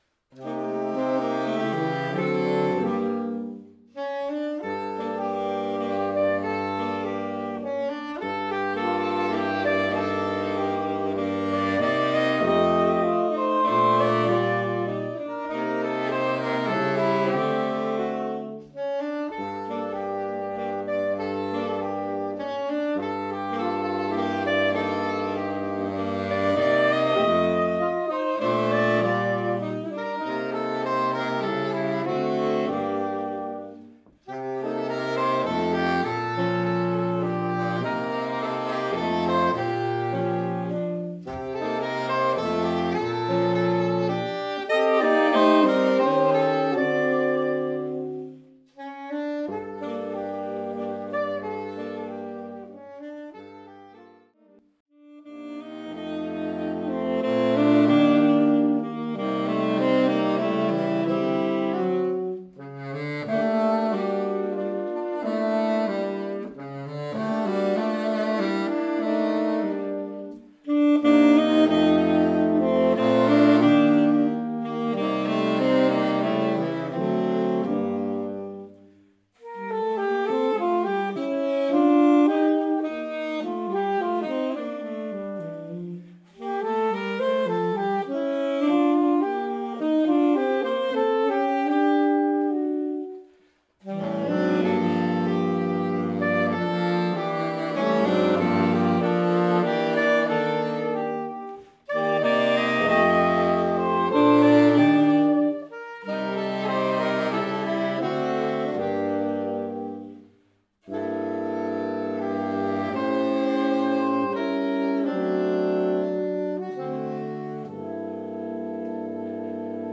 Einige Titel sind mit Hörbeispielen hinterlegt (MP3), die mit kleiner Technik und live aufgenommen wurden. Diese Beispiele sind durch Ein- bzw. Ausblendungen gekürzt.
(4) melancholisch U. Neumann